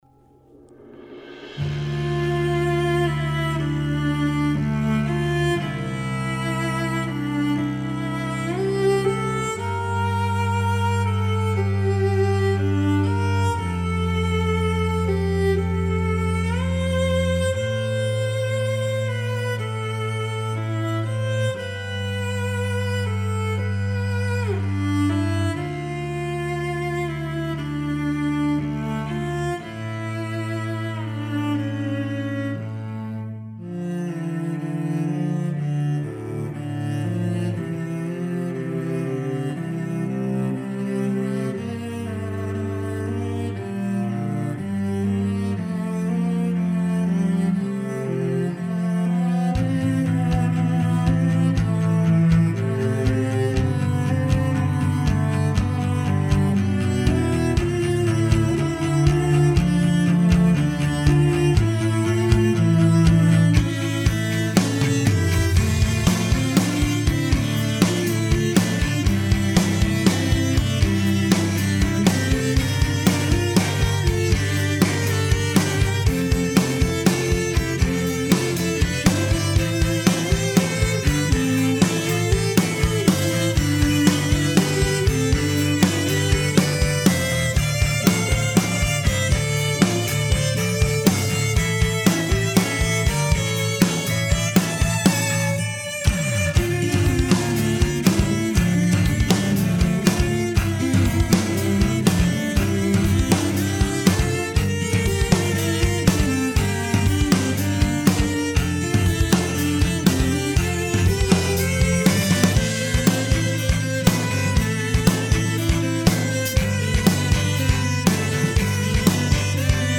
Interview: Songspace Preview Cello Fury